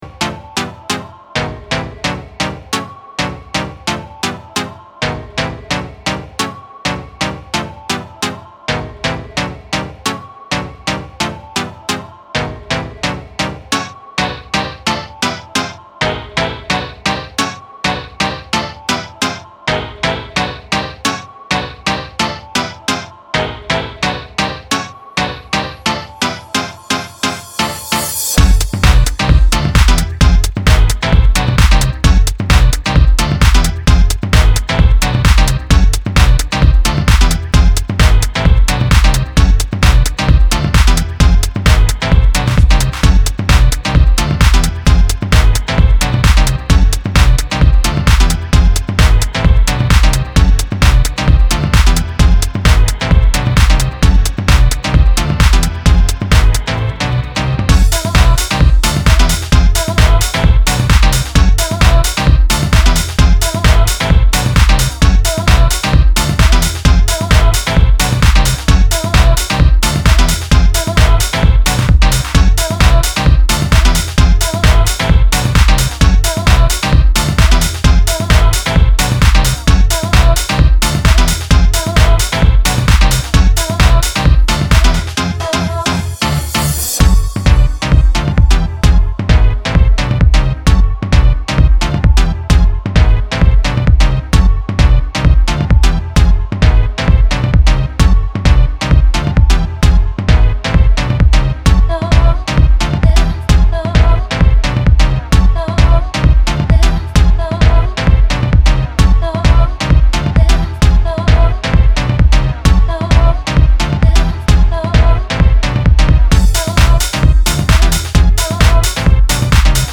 レイヴィーなセットに起用すべき一枚です。